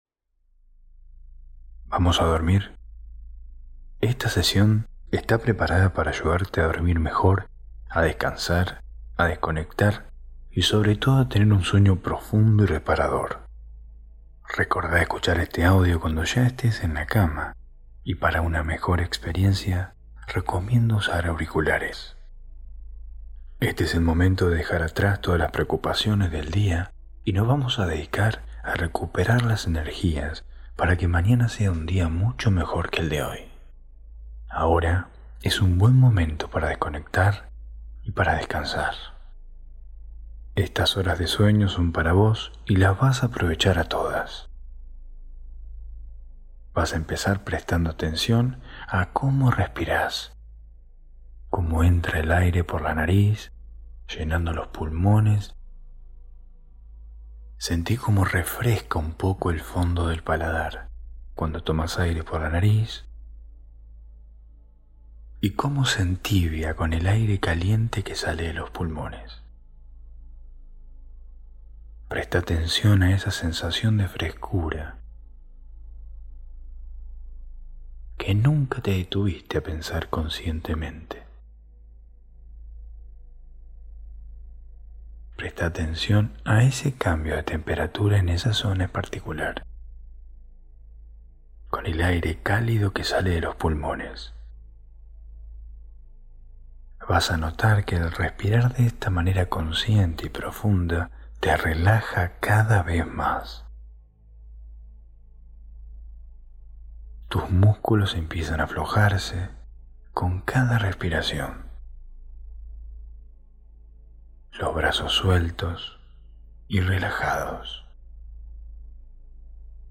Hipnosis para Dormir 6